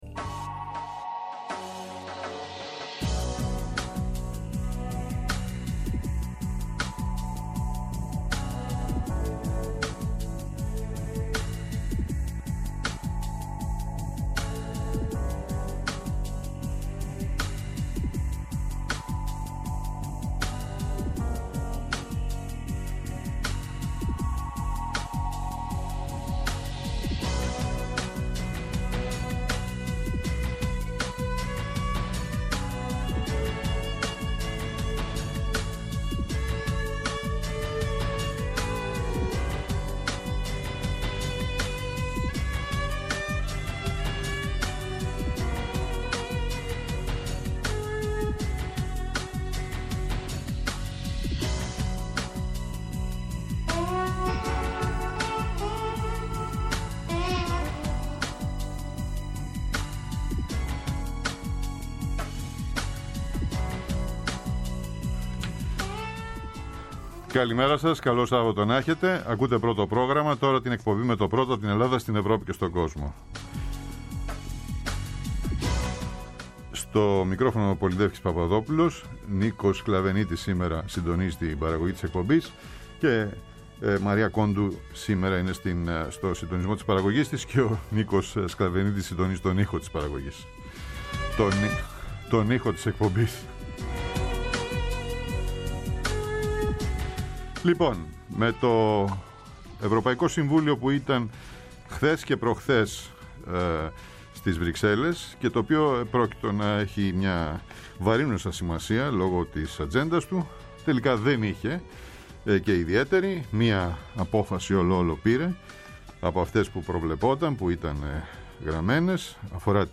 Ο απολογισμός του τελευταίου, ιδιαίτερα κρίσιμου, Ευρωπαϊκού Συμβουλίου για την έναρξη ενταξιακών διαπραγματεύσεων με Ουκρανία και Μολδαβία, τον συμπληρωματικό προϋπολογισμό, το μεταναστευτικό, τη σύγκρουση στη Γάζα και την αναθεώρηση του πλαισίου Οικονομικής Διακυβέρνησης της Ευρωζώνης και τελικά εν μέρει αποφάσεις μόνο για τα ενταξιακά. Καλεσμένος, ο Λουκάς Τσούκαλης, Ομότιμος Καθηγητής ΕΚΠΑ-Καθηγητής στη Σχολή Διεθνών Υποθέσεων του Παρισιού (Sciences Po) και Πρόεδρος του ΕΛΙΑΜΕΠ εξηγεί τη σημασία της απόφασης που ελήφθη, αλλά και όσων για τα οποία υπήρξε διαφωνία ή αναβολή.